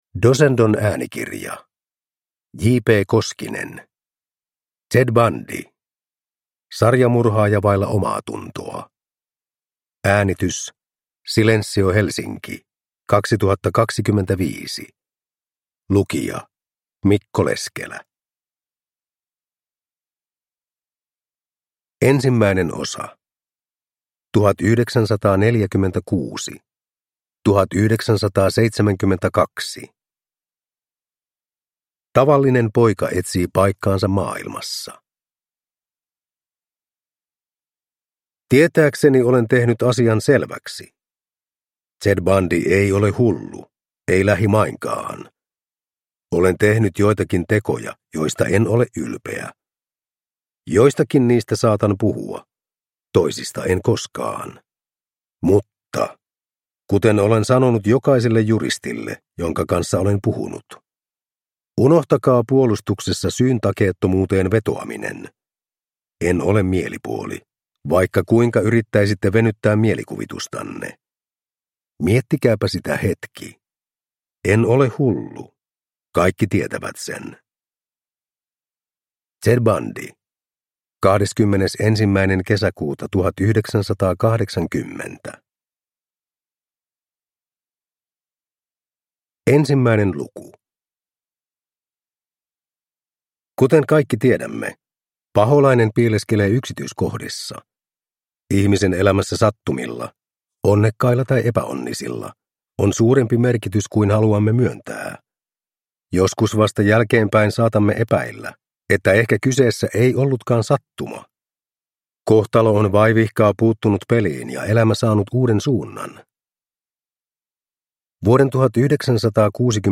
Ted Bundy (ljudbok) av JP Koskinen